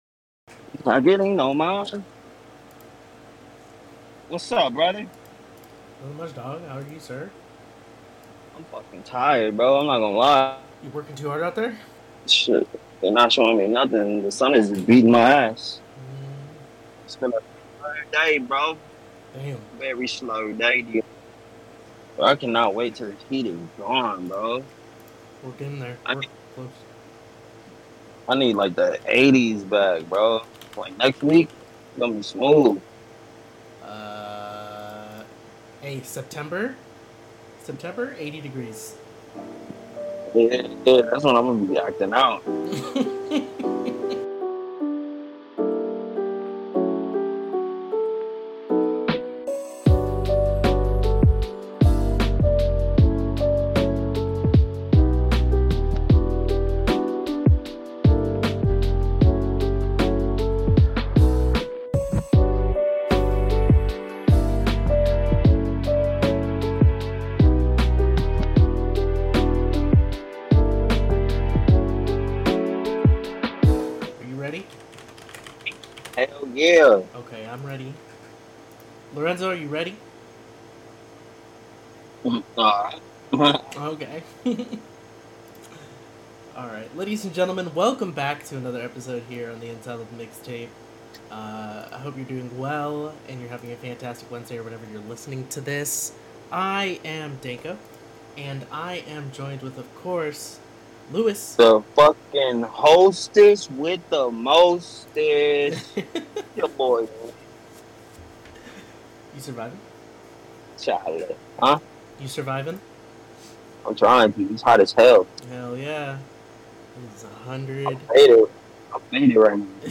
And also a low quality Air Conditioner can be heard in the background to give its opinion